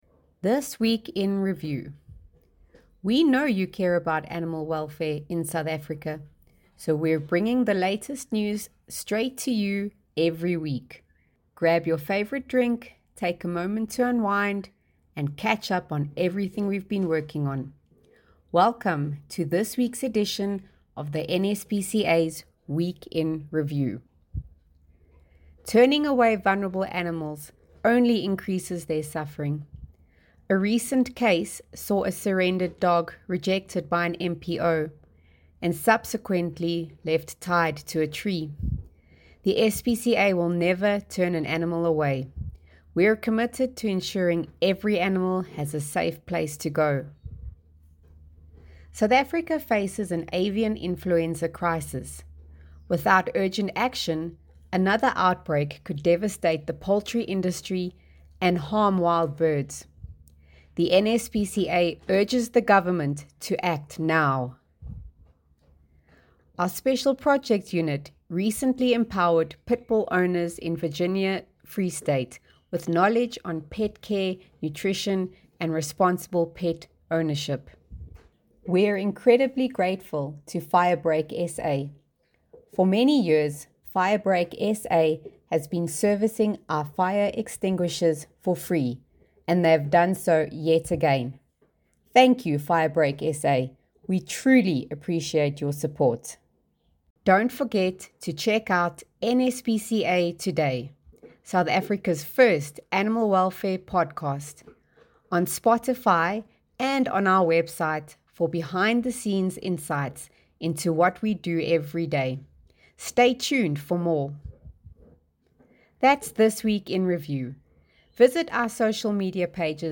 Ed.-2-Voiceover.mp3